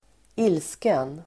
Uttal: [²'il:sken]